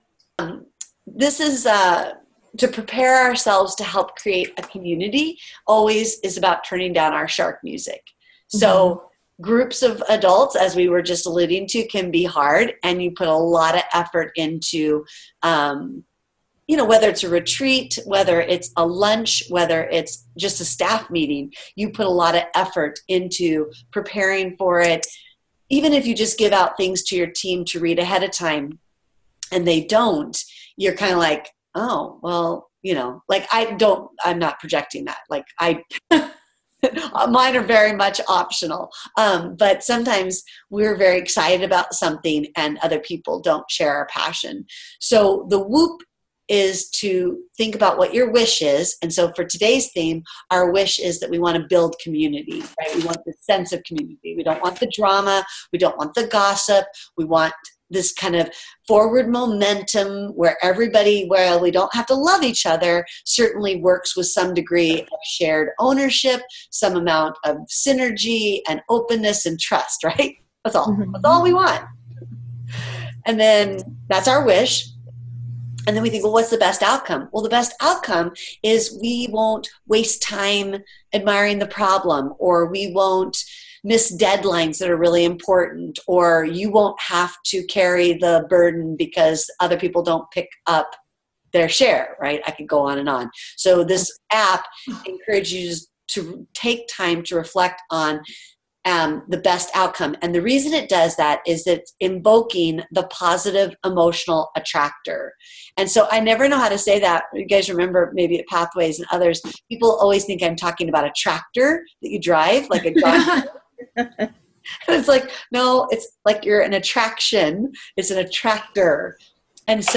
here for an audio except where I was teaching about how to use the WOOP app mentioned in Jonathan’s Know What Matters chapter.